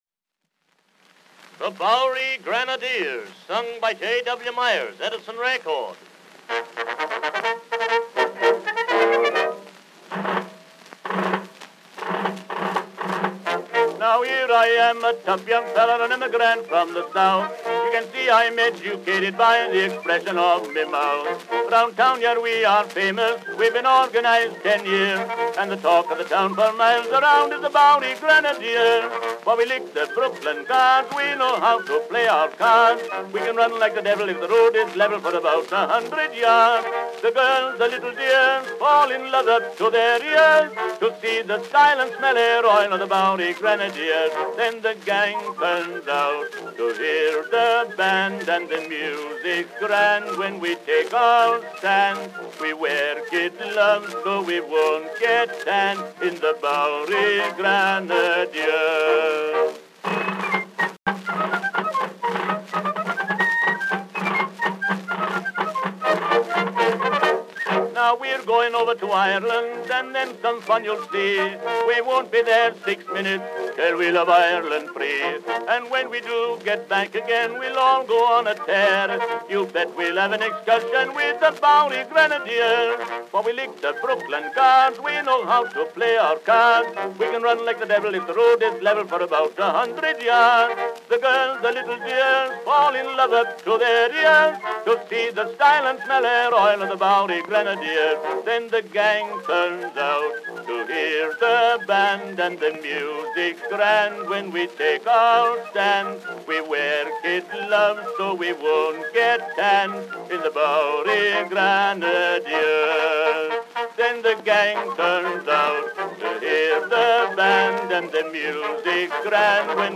Vocal selection (march song).
Popular music—1901-1910.
Marches.